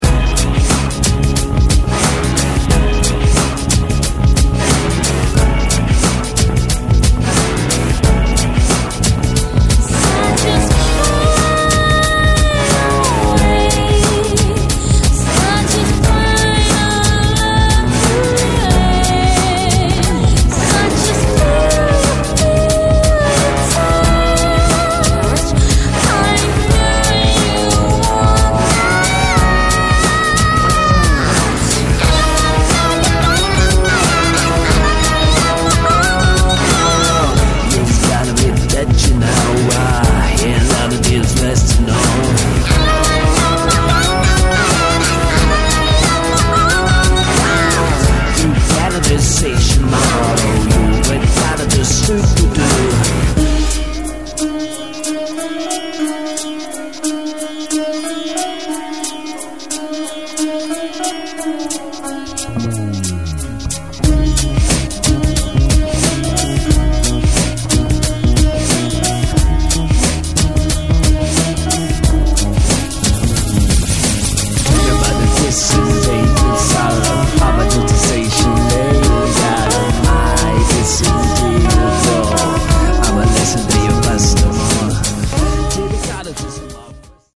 экстрим, замешанный на акварельной печали; трагикомедия в прожилках черно-белых тонов, приправленная иронией к стилистике и общепринятым формам